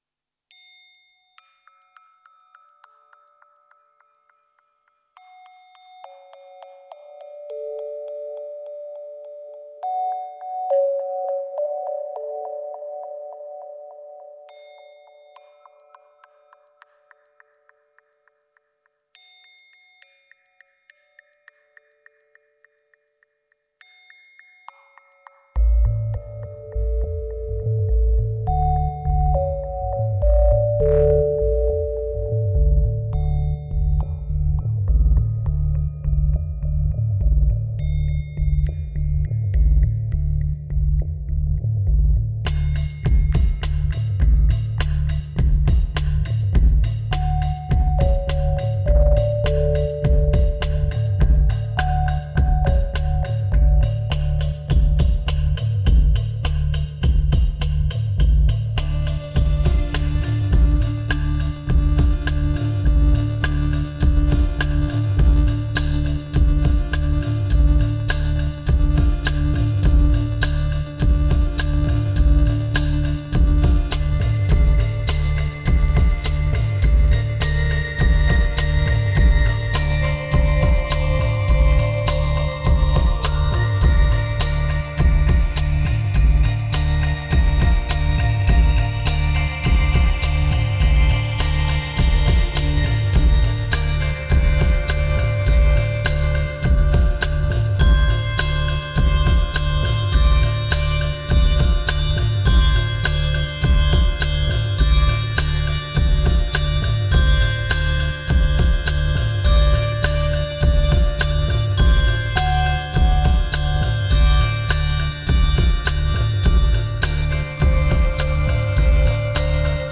1838📈 - 60%🤔 - 103BPM🔊 - 2013-03-10📅 - 78🌟